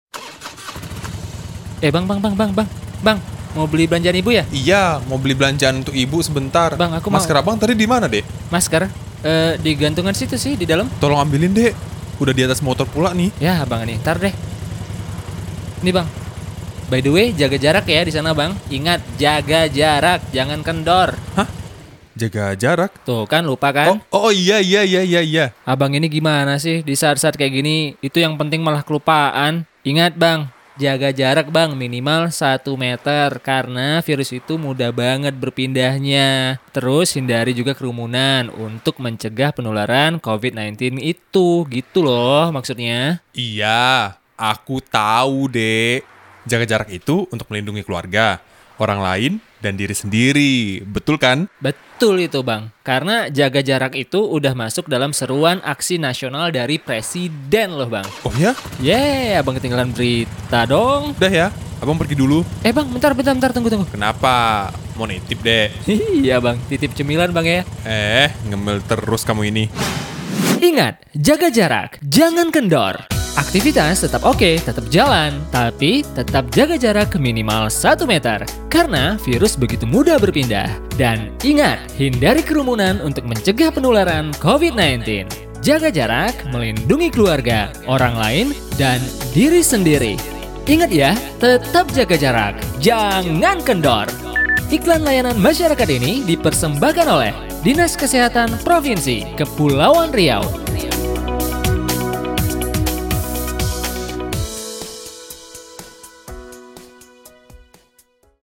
Casual Character Indonesia INDONESIA_CASUAL INDONESIA_CHARACTER